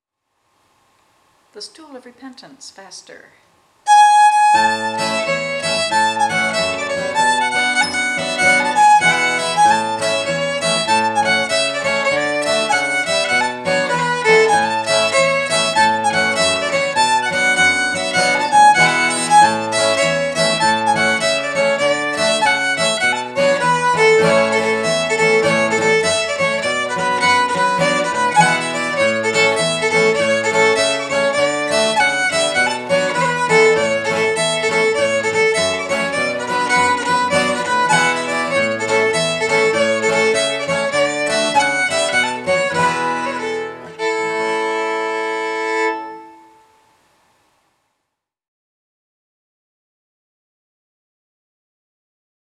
guitar accompaniment
Stool of Repentance, Faster
StoolRepentanceFasterA.aif